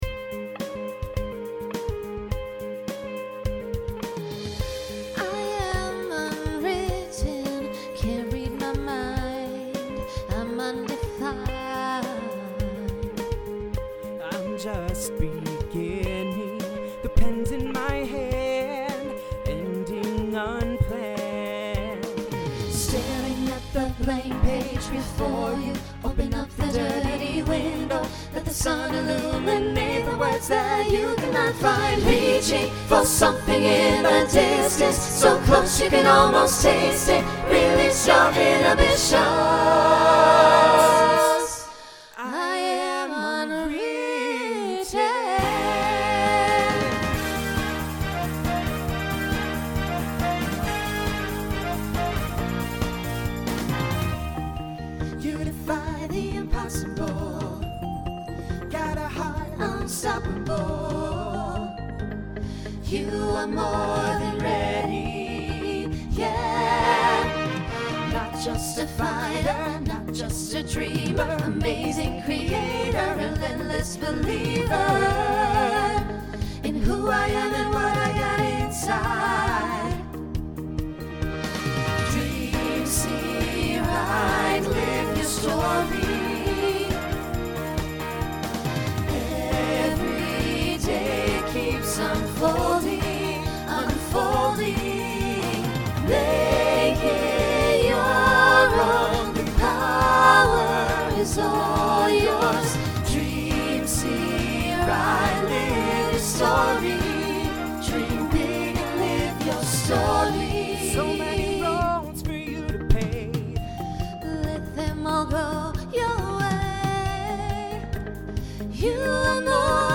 Genre Pop/Dance
Voicing SATB